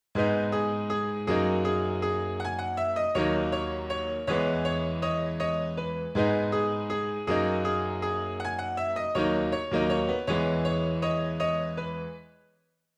Dro Strobe - BPM 160.wav